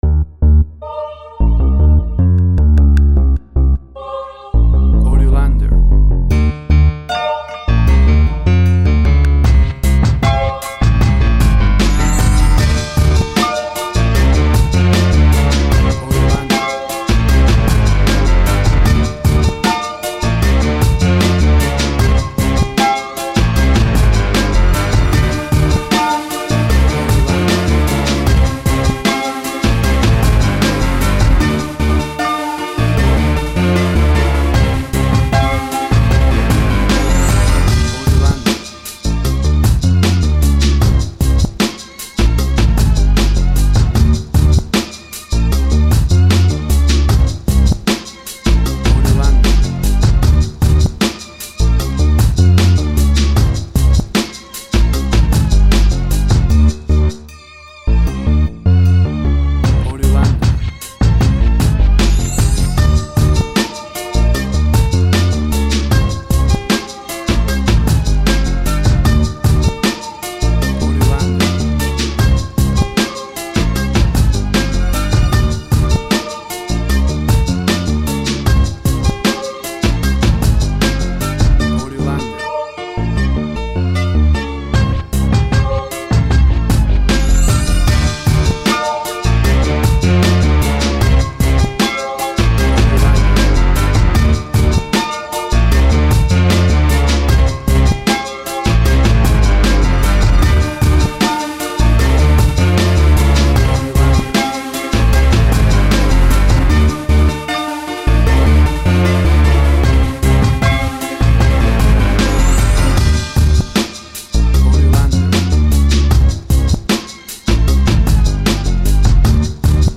Tempo (BPM): 77